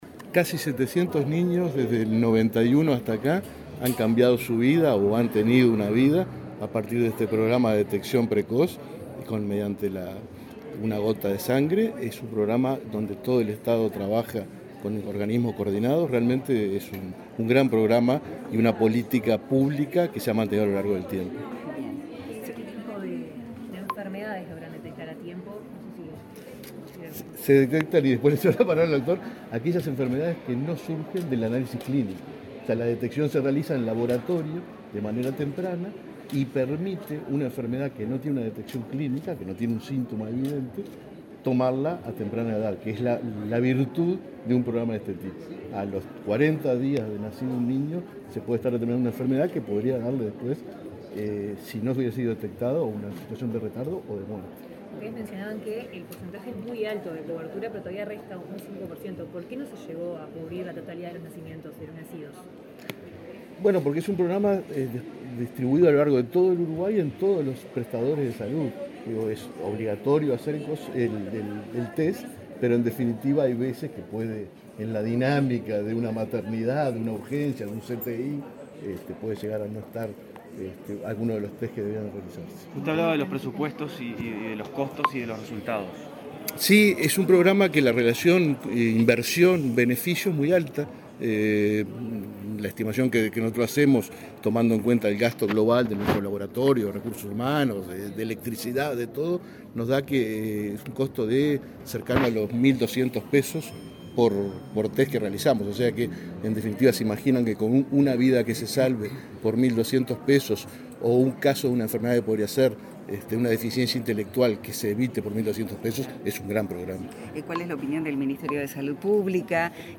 Declaraciones a la prensa del titular del BPS y del director general de Salud del MSP